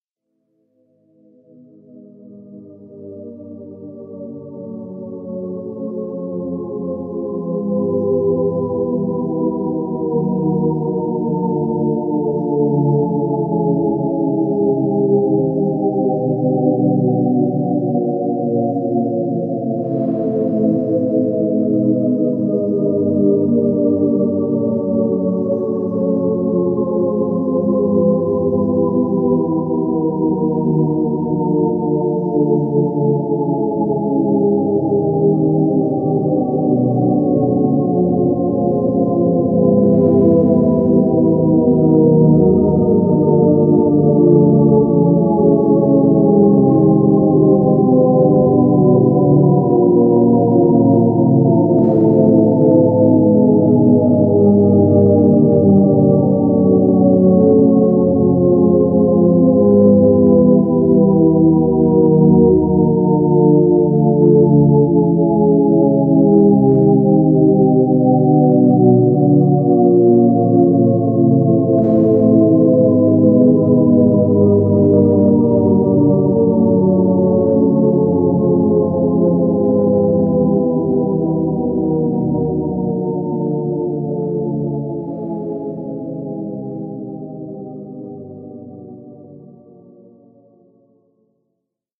私の抱く「アルベルチーヌ」のイメージに非常に迫る音でありながら、それを更に超越した捉えようのない距離感も同時に備えている。
時に私に慰めを与え、時に挑発的に裏切る「毒」を含んだ緊迫感溢れる音の嵐が始まりも終わりもなく脳を直撃し、身体は熱を帯び浮遊し始める。